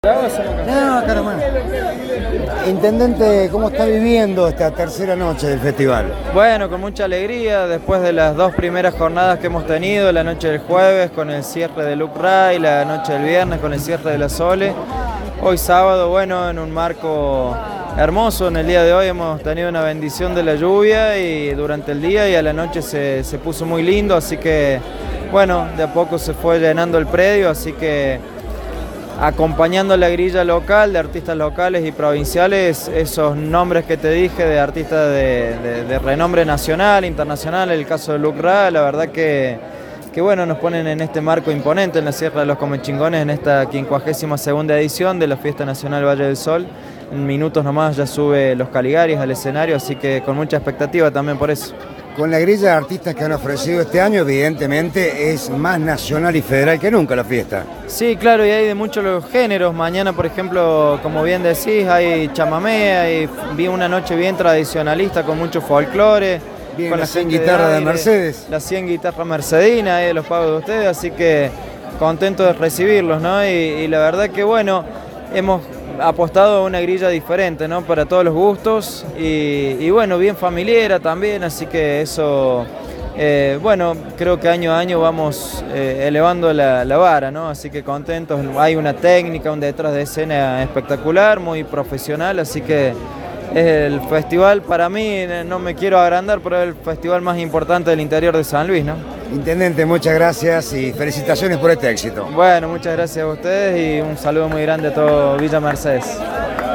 La satisfacción de los organizadores por el desarrollo del festival fue expresada por el intendente interino Leonardo Rodríguez, consultado por Apuntes de San Luis el sábado por la noche, en la previa de la actuación de “la banda más divertida del país”.